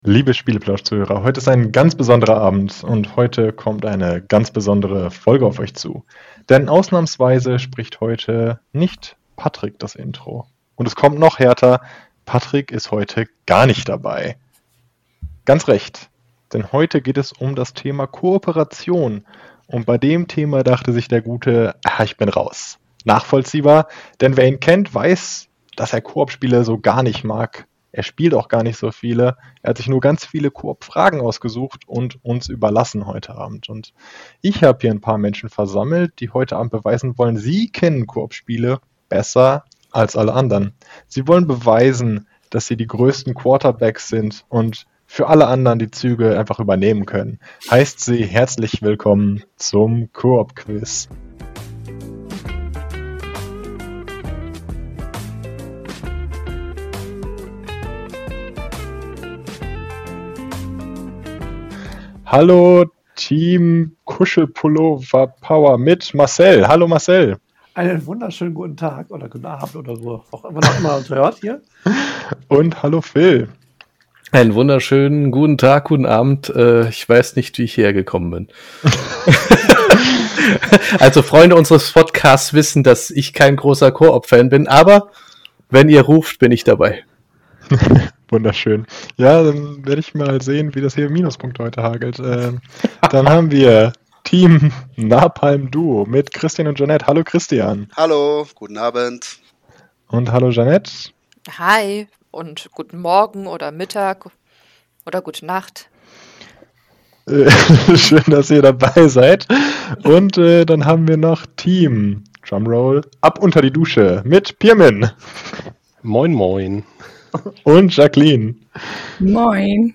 Wir Quizzen wieder um die Wette. Wer kennt sich mit Coop Spielen am besten aus?